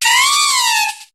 Cri de Lianaja dans Pokémon HOME.